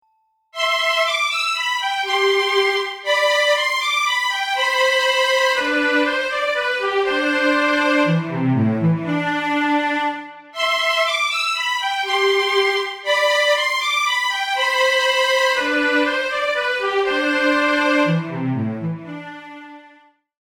A tone poem in five movements.